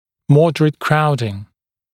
[‘mɔdərət ‘kraudɪŋ][‘модэрэт ‘краудин]умеренная скученность, средняя скученность